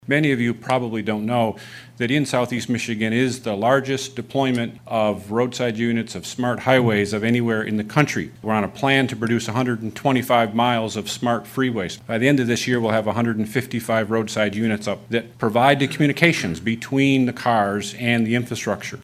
News from the Mackinac Policy Conference — the revealing of a new marketing campaign to promote the state of Michigan as the center of “next generation mobility”.
MDOT Director Kirk Steudle spoke about “Planet M” to promote autonomous and connected vehicle technologies.